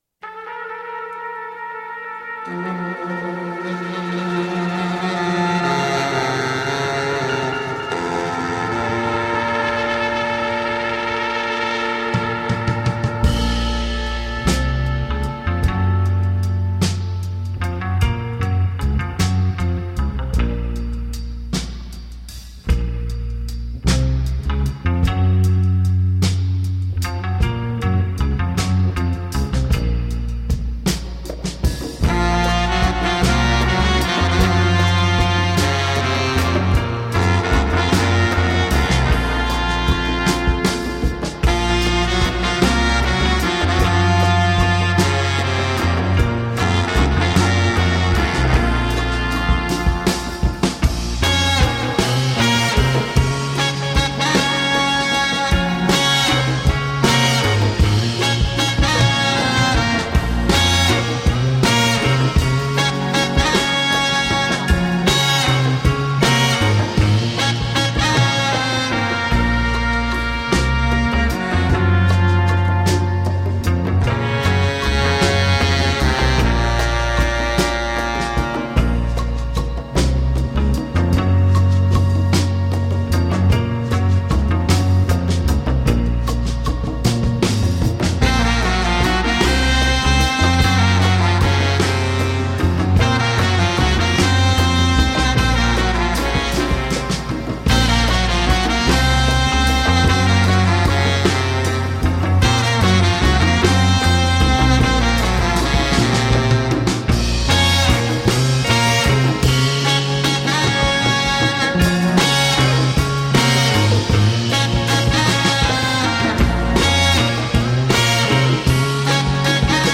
A tasty Afrobeat podcast